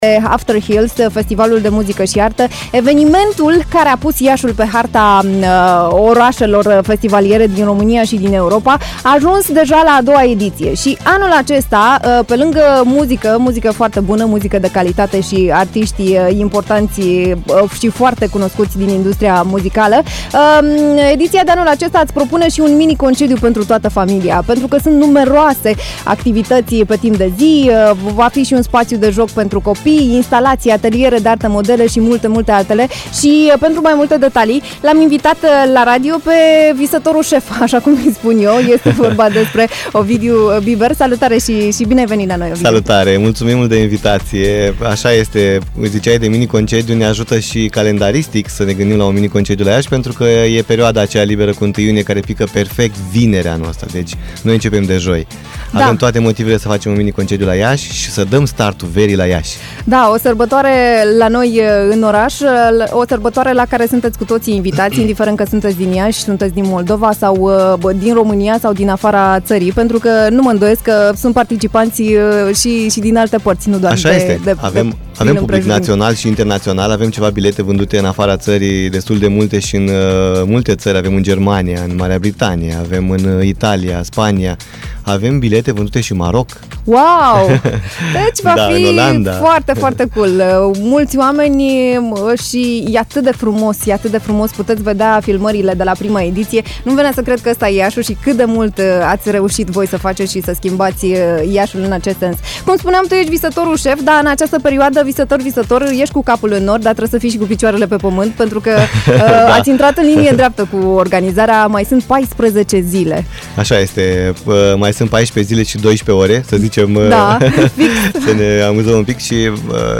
In Be the HIT, am stat de vorbă